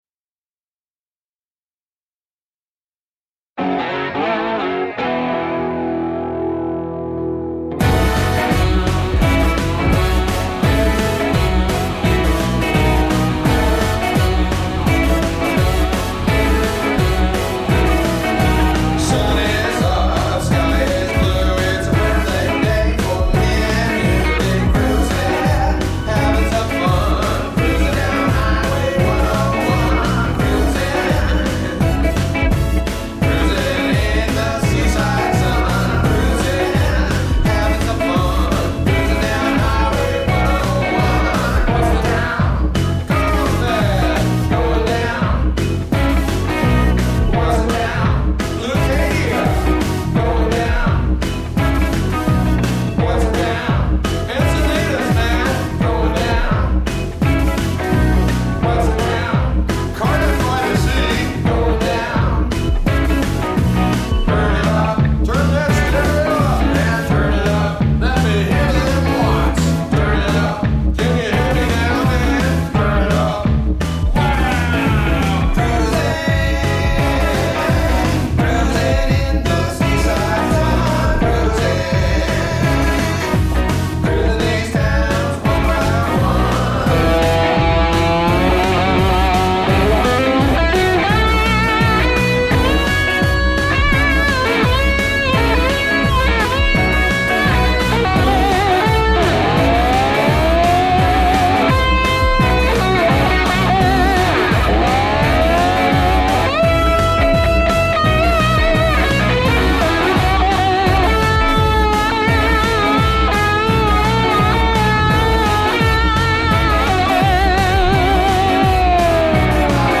Rock & Roll